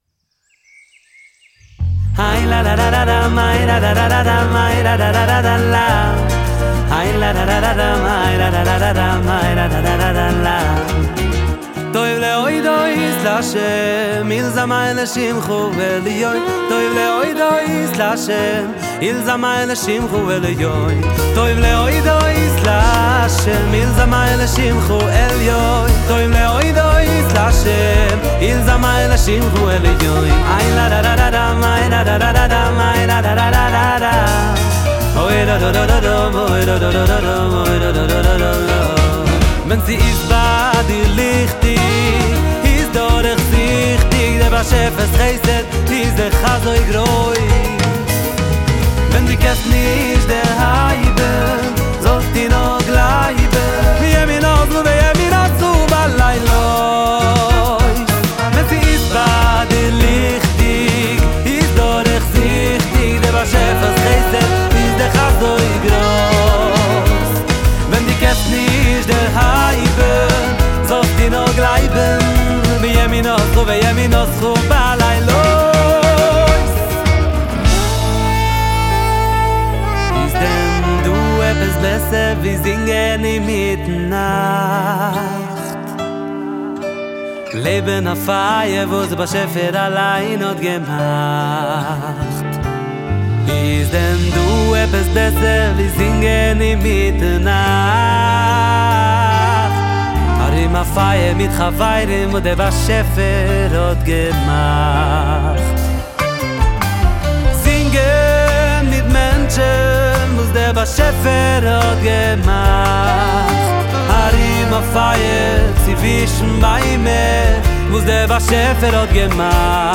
שירה
גיטרות ובס
תופים
נשיפה